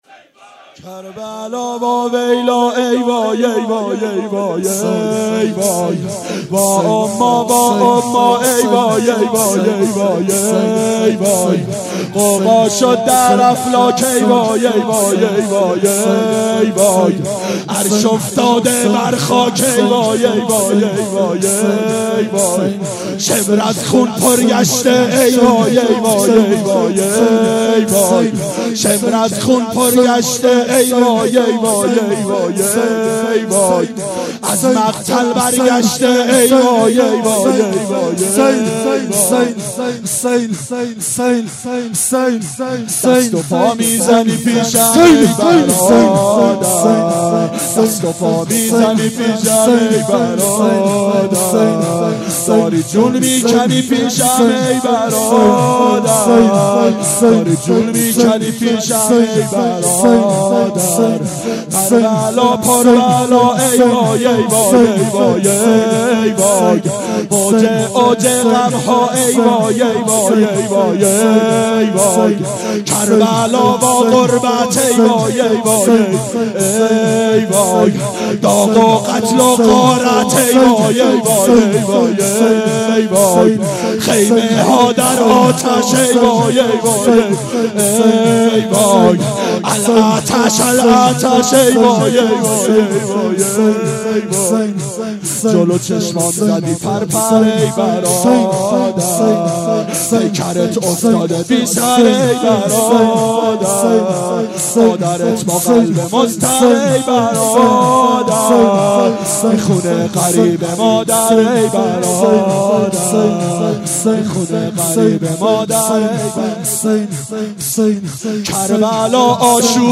شب عاشورا 1391 هیئت عاشقان اباالفضل علیه السلام